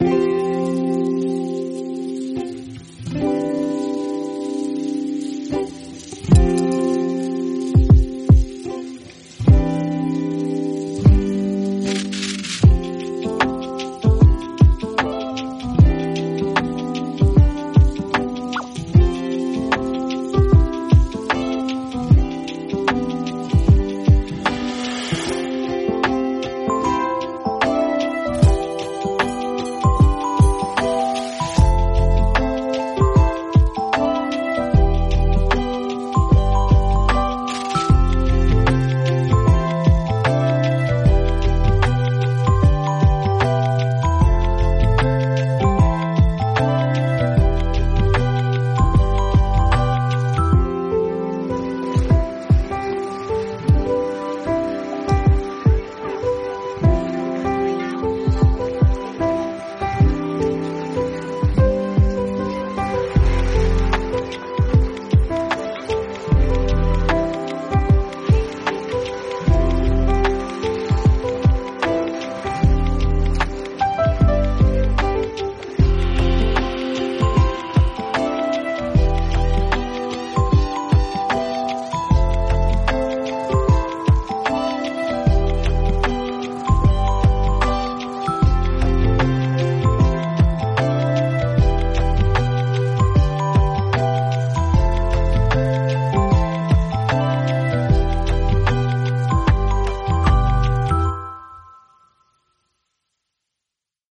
Each devotional is paired with a calming track